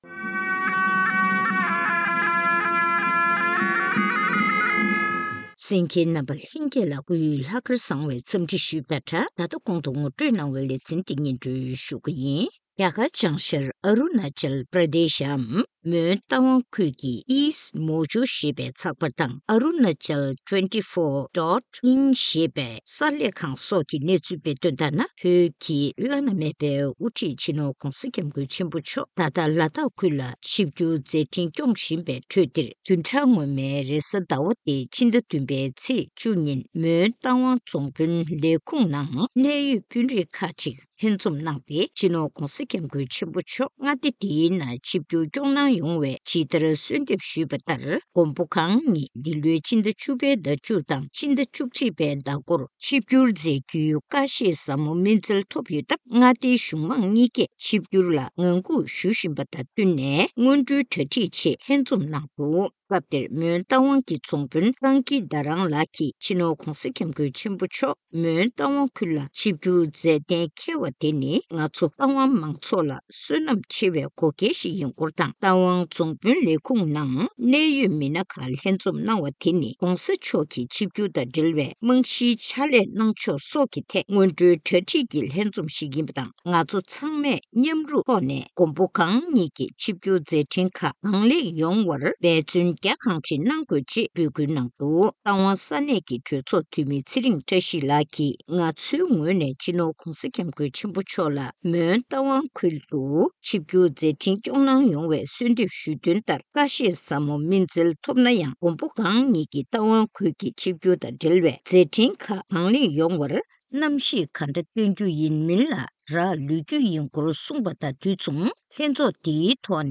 གནས་འདྲི་ཞུས་ནས་གནས་ཚུལ་ཕྱོགས་ཞུས་པ་ཞིག་གསན་གནང་གི་རེད།